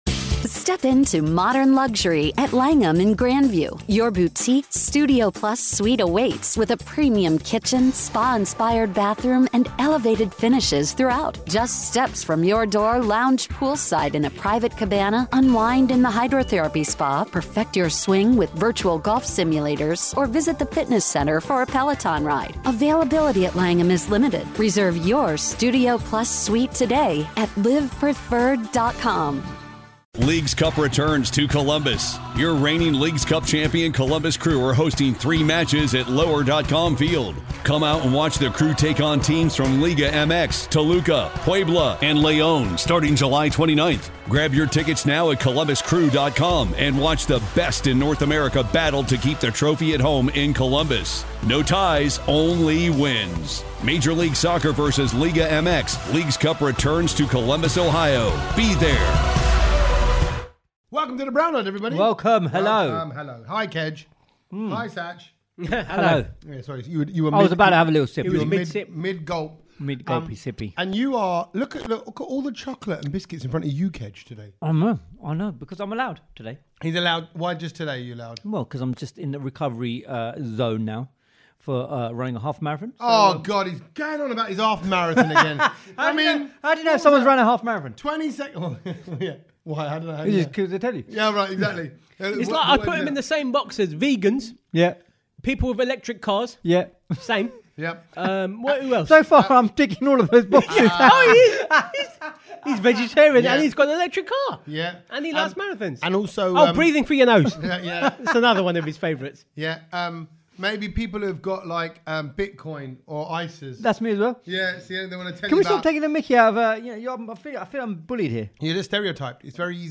We’re in the garage talking Storms, Morocco and Vaisakhi!